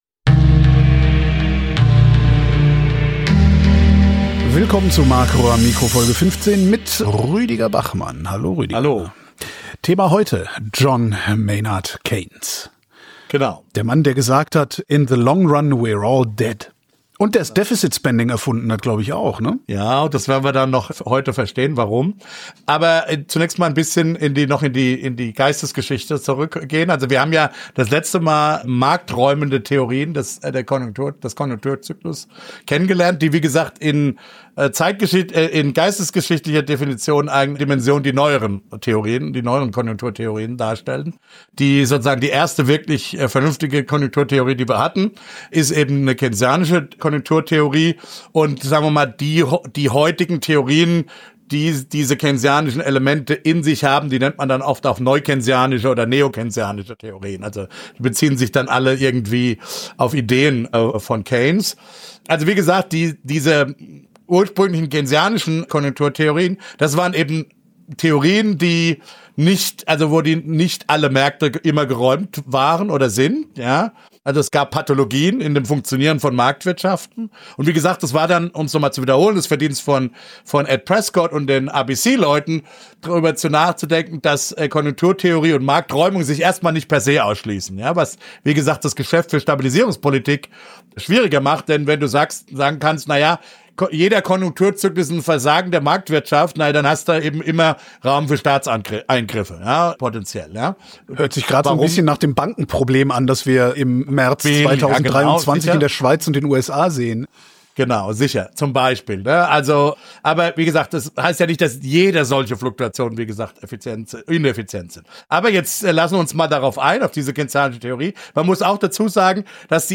Weil in jeder Sendung geredet wird, habe ich mir erlaubt, das Projekt nach einem Satz aus Gottfried Benns Gedicht “Kommt” zu benennen.